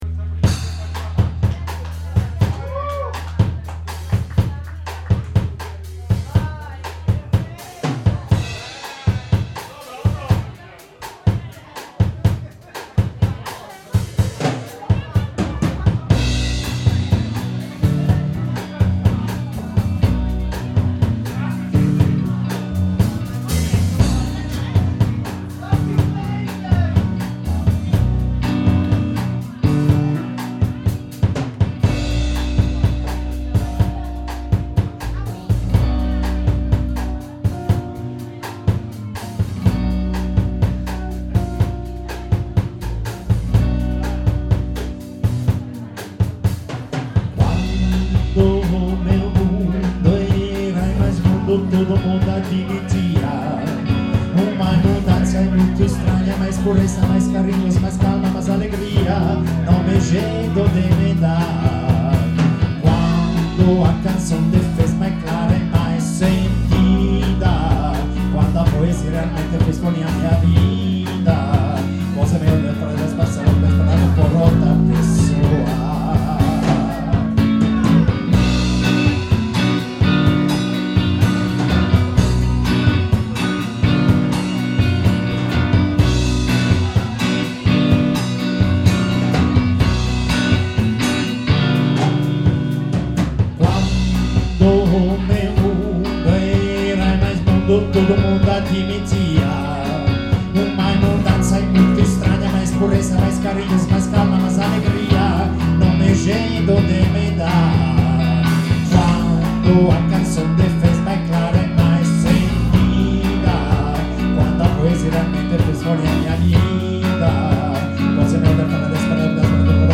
with ORTF stereo configuration,